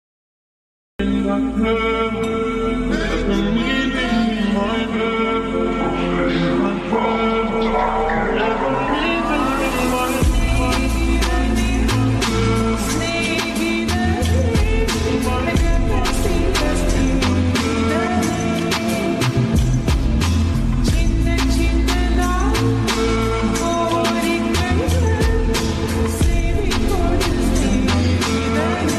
Lofi Ringtone Ringtone For Your Mobile Phone
Alternative Ringtones